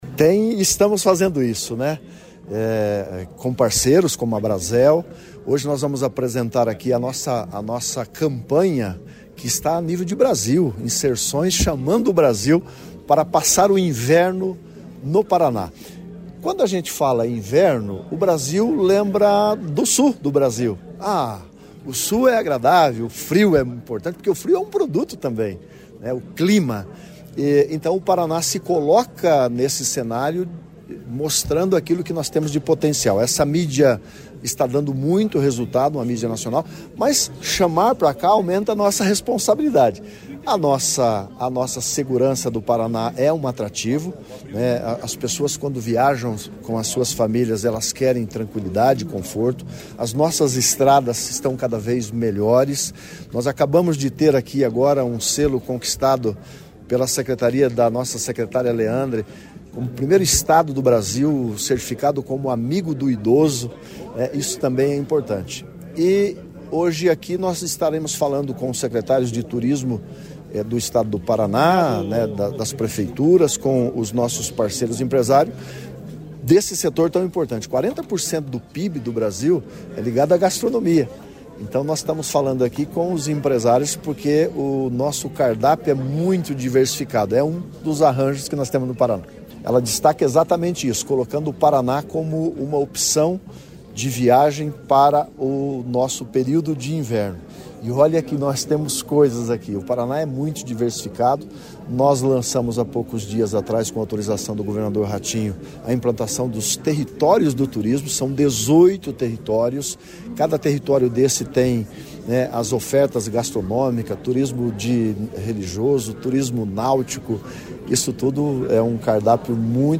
Sonora do secretário Estadual do Turismo, Leonaldo Paranhos, sobre o fórum para fortalecer o turismo do inverno no Paraná